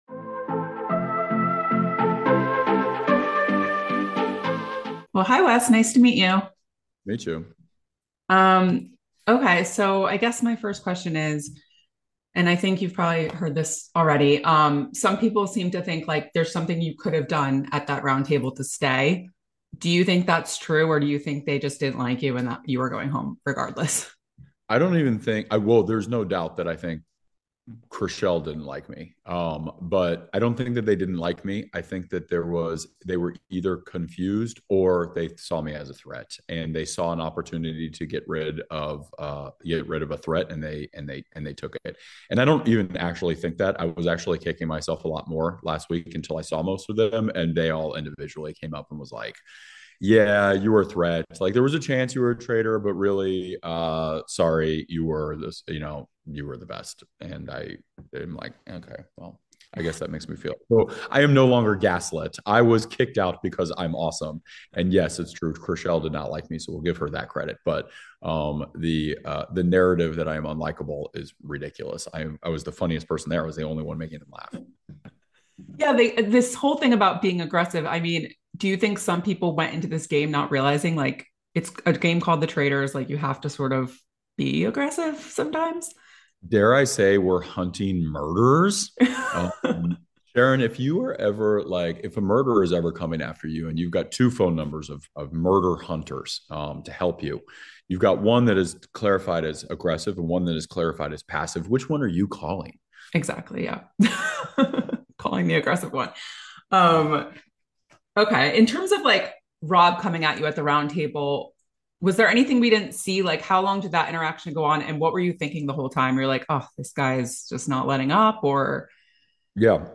The Traitors Exit Interview: Wes Bergmann Talks 'Aggressive' Accusations, Boston Rob Showdown